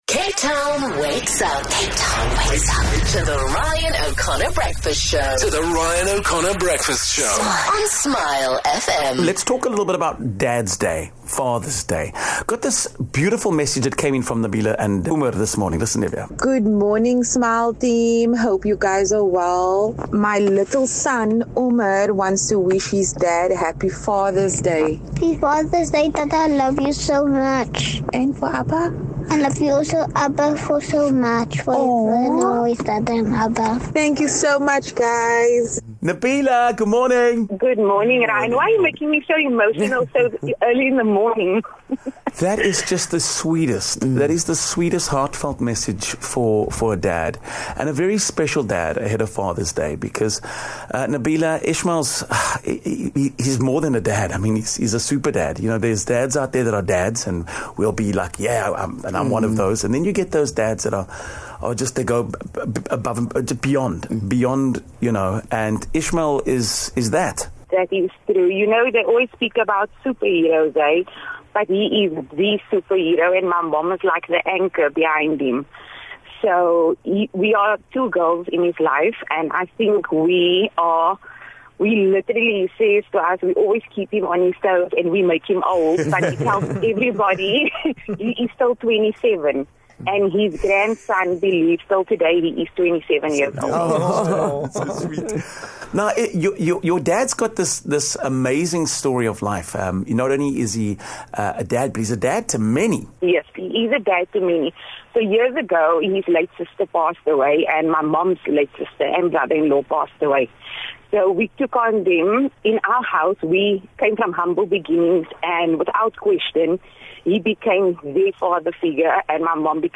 a heartwarming Father's Day call